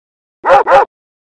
The sound effect made by a Chain Chomp enemy as heard in the Super Mario series